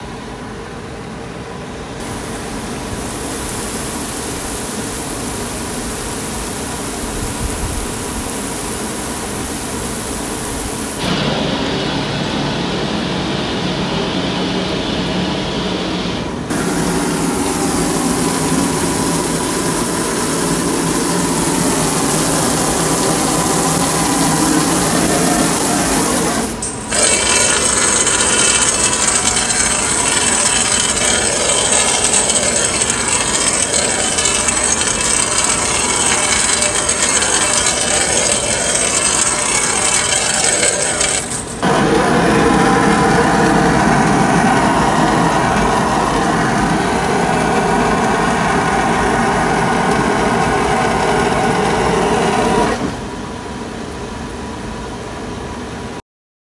Im Innern nimmt man akustisch und visuell die Wasserstrahlen eines Wasserspiels und das Rauschen eines Wasservorhangs wahr.
Wasserstrahlen werden mit hohem Druck an die Glaswand des Tunnels gespritzt, sodass ein Akustikerlebnis entsteht.
Akustik-Wasserspiel.mp3